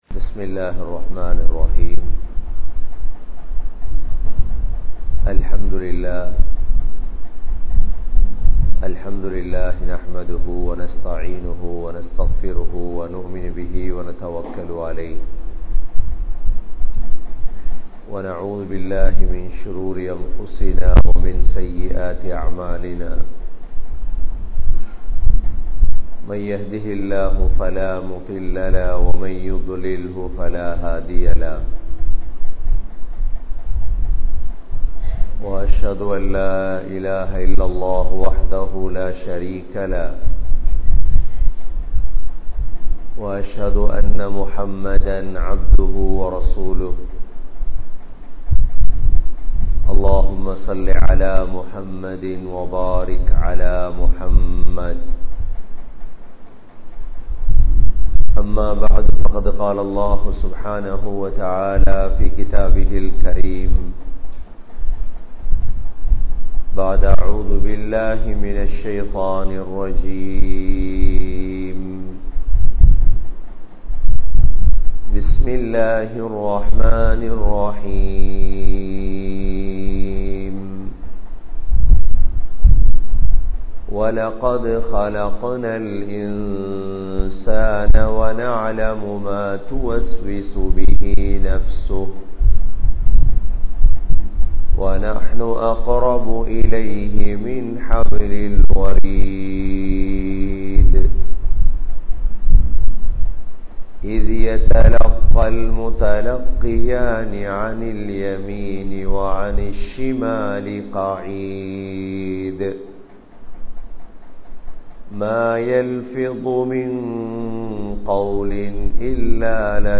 Eattru Kollappatta Maarkam ISLAM (ஏற்றுக் கொள்ளப்பட்ட மார்க்கம் இஸ்லாம்) | Audio Bayans | All Ceylon Muslim Youth Community | Addalaichenai